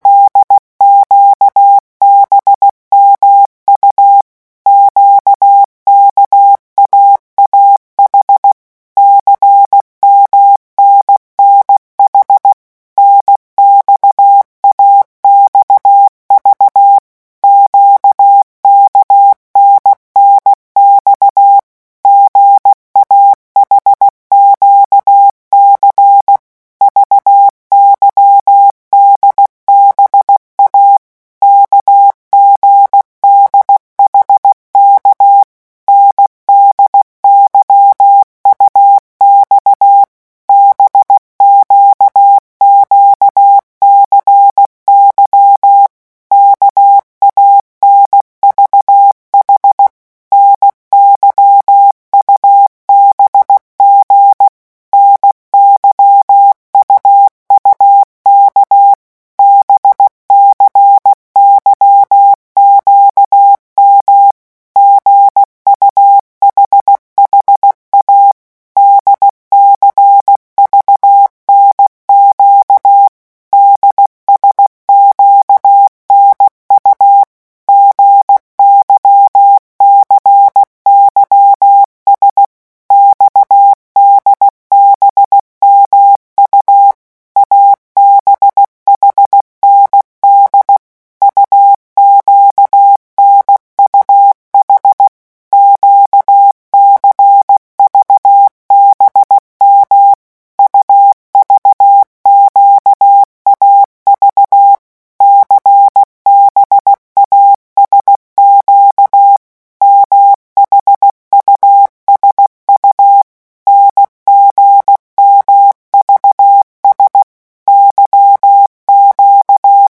CODE MORSE - REVISION 4
15 mots minutes
revision4-vitesse_15_mots.mp3